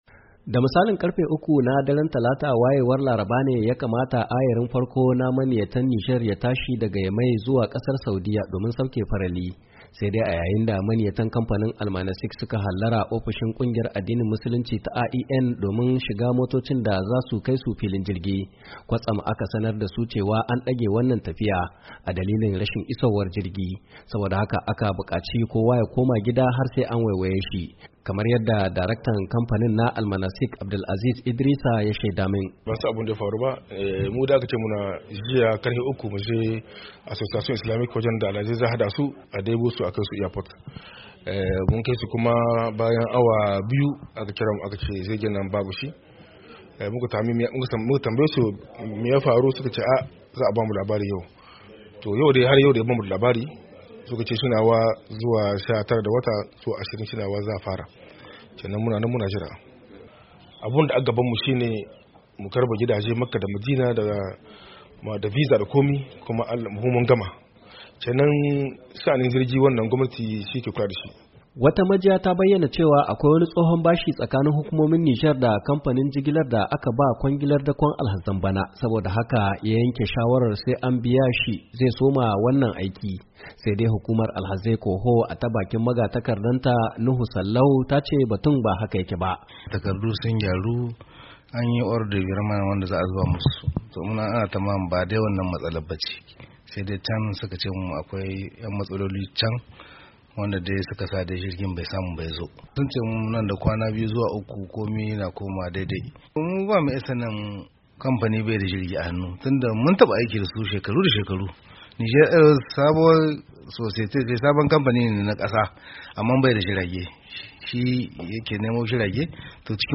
Domin Karin baya ni, ga rahoton